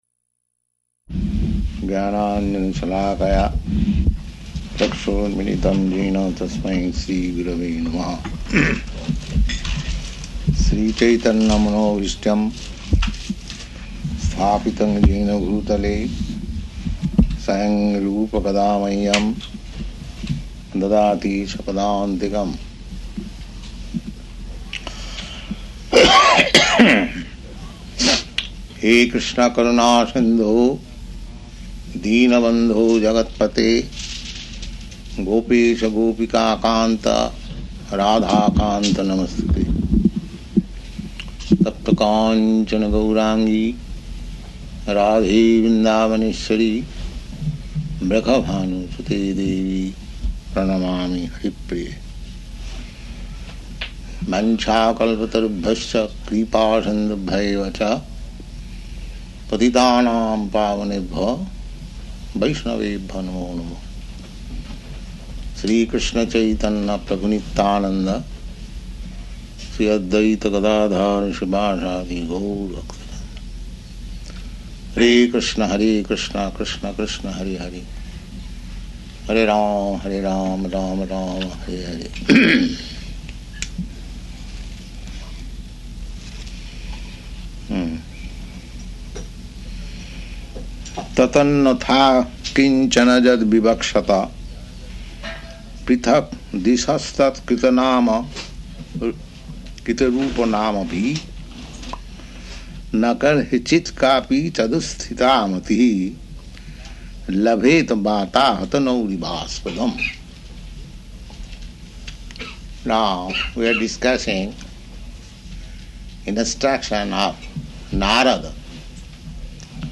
Location: New Vrindavan
[chants maṅgalācaraṇa prayers]